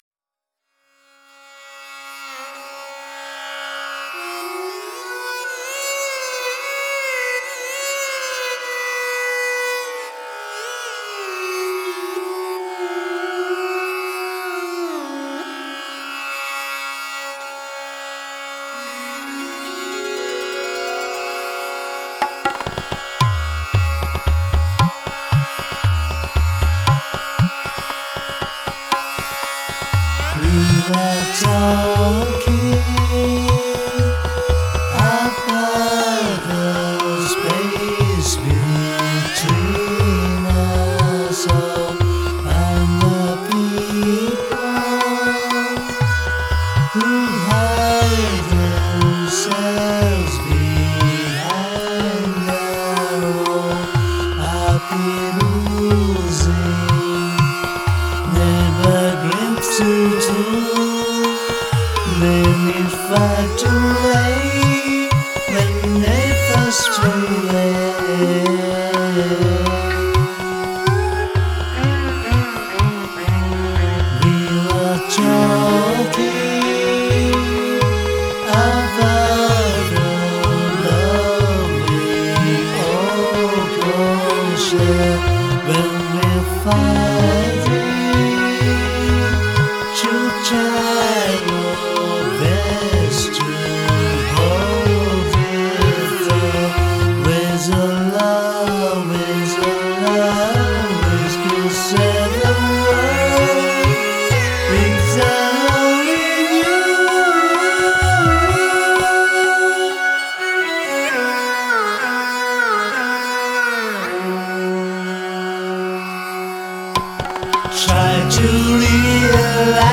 MTR：Zoom MRS1266
Amp Simulater：Line6 POD2
Microphone：Shure SM58